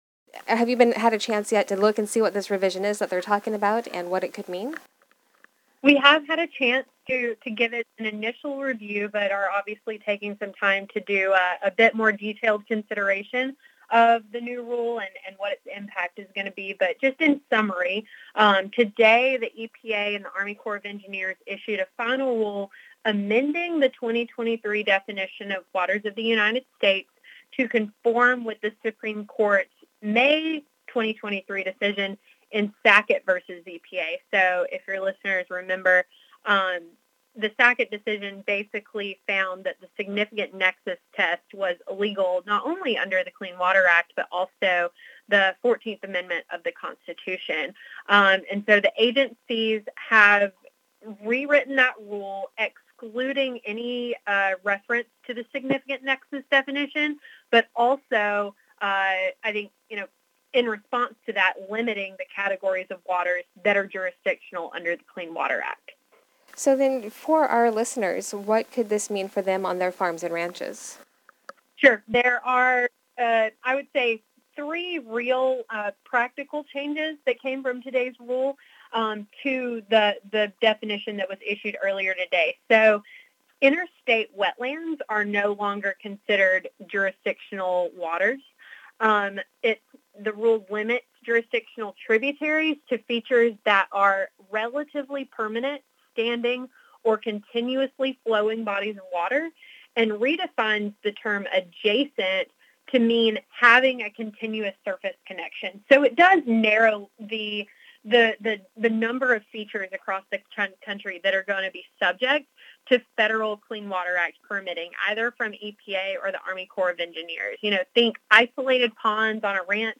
NCBA-WOTUS-Interview.mp3